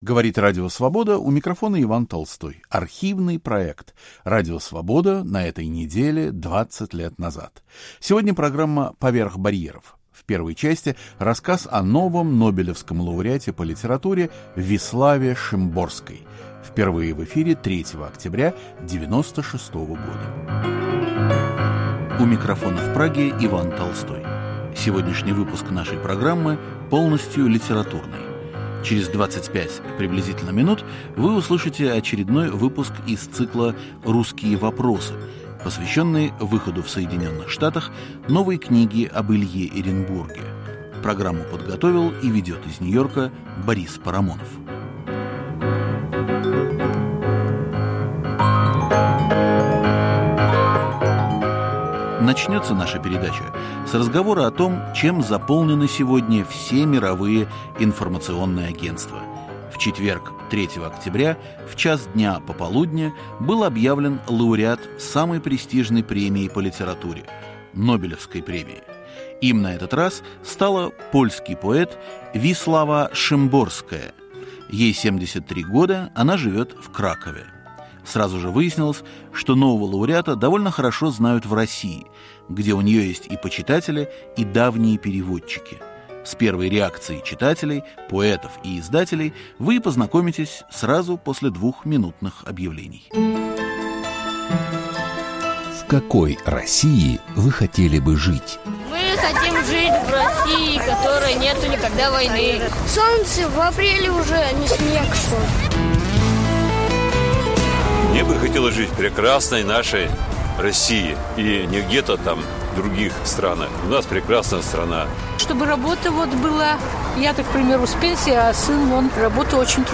Ведет передачу и читает стихи